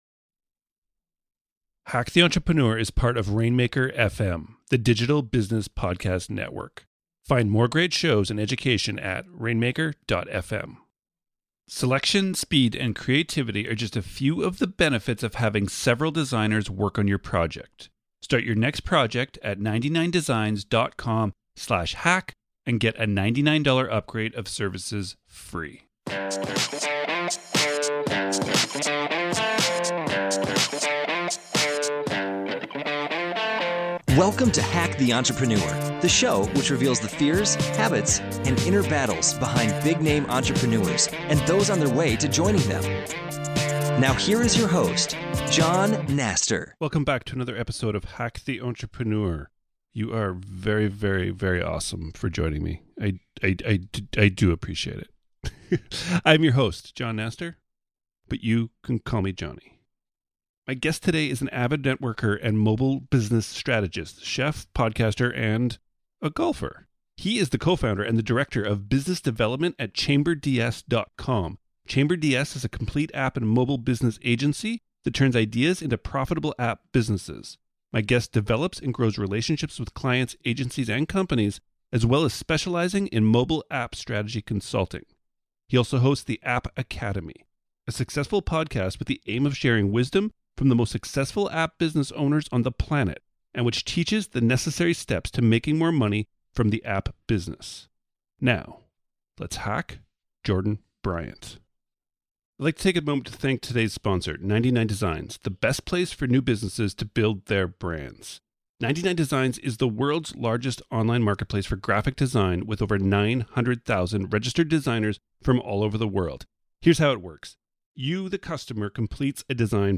My guest today is an avid networker and mobile business strategist, chef, podcaster and golfer.